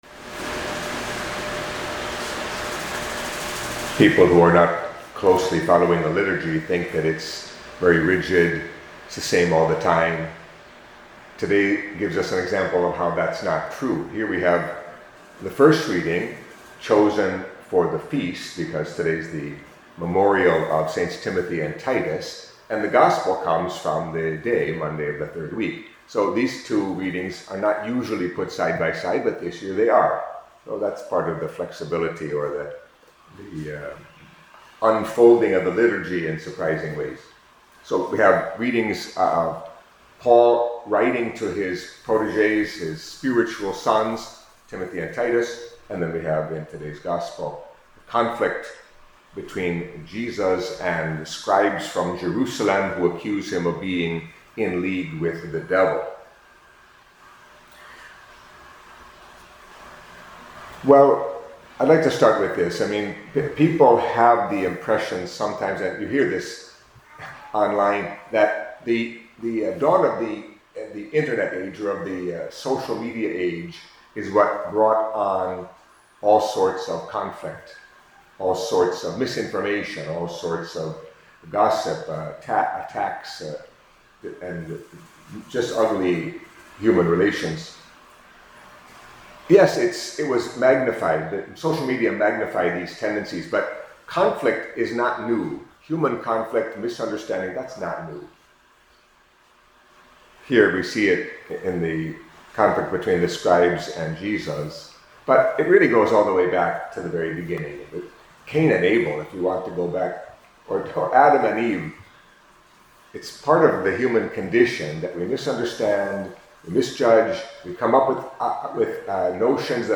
Catholic Mass homily for Monday of the Third Week in Ordinary Time